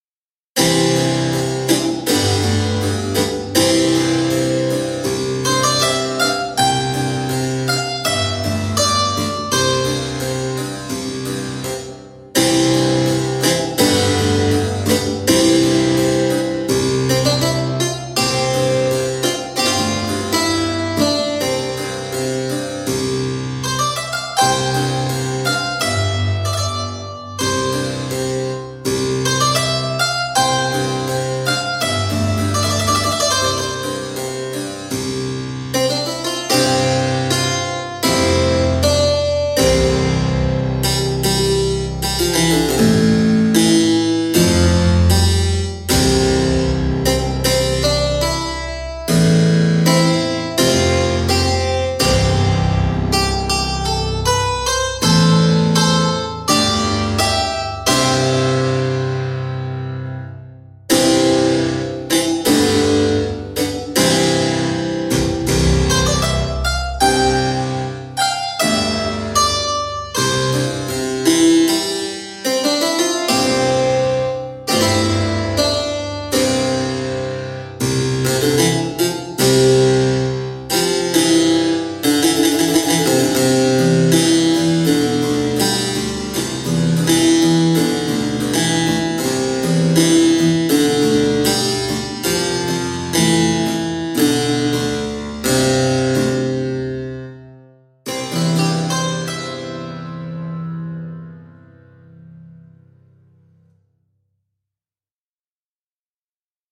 羽管键琴 Soundiron Harpsichord KONTAKT-音频fun
Soundiron Harpsichord 是一款基于 Kontakt 的虚拟键盘音源，它采样了一架 18 世纪的意大利 Bizzi 大键琴，拥有历史悠久的巴洛克风格的声音和氛围。这款音源不仅提供了原始的大键琴音色，还包含了由大键琴声音加工而成的多种环境垫音、演变无人机、冲击音效等，适合用于创造性的音乐制作和声音设计。
- 多采样的大键琴音符，包括 9 个力度层、6 个轮换、释放音、簇音、滑音、无音键和踏板噪音
Soundiron-Harpsichord.mp3